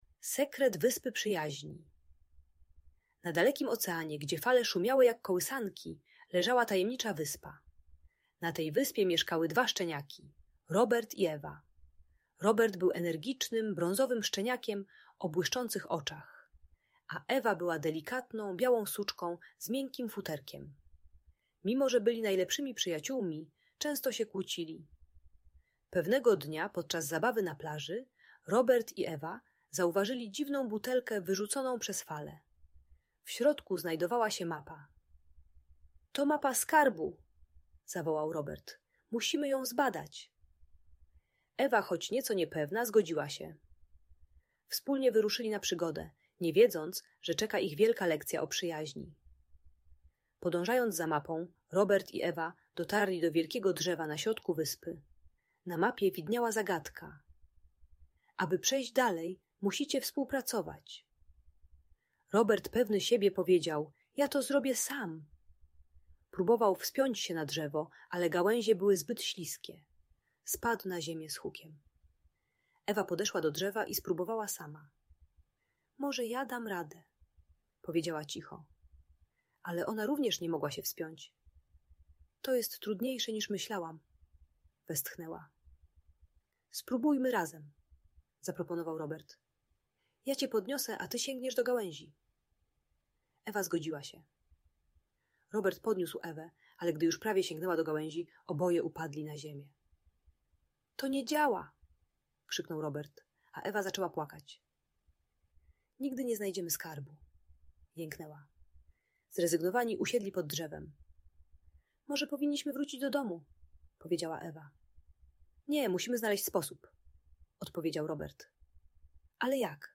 Sekret Wyspy Przyjaźni - Audiobajka